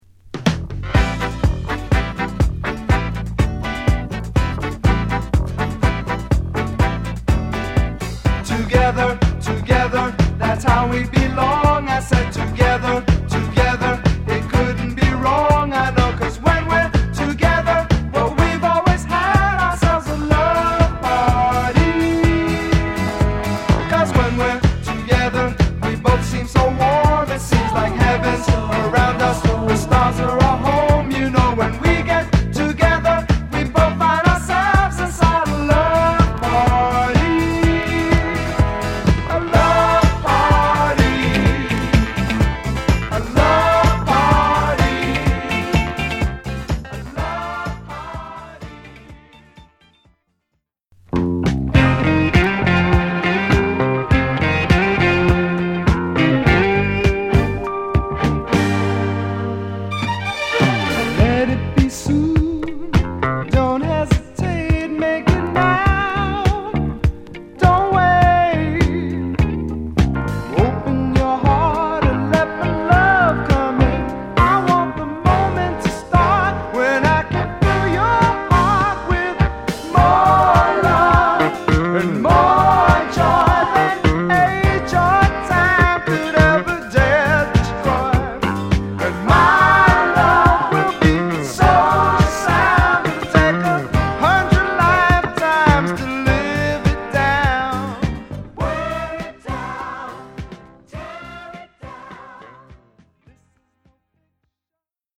メロウからダンサブルなものまでバランス良く収録した本作。